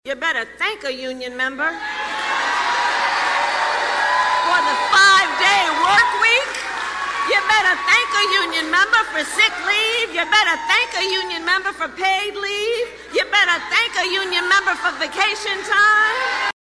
AUDIO: Vice President Kamala Harris campaigns in Detroit
Harris spoke in the gym at Northwest High School.